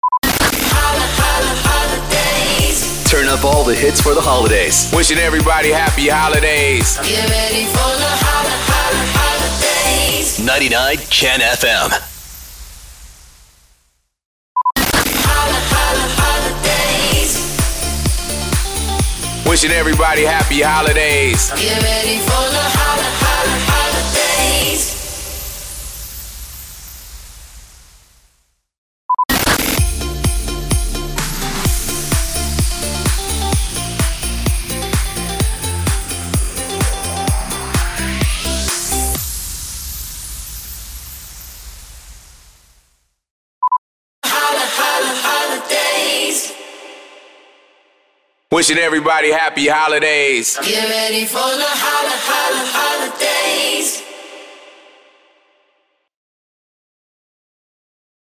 557 – SWEEPER – HOLIDAYS – CHRISTMAS
557-SWEEPER-HOLIDAYS-CHRISTMAS.mp3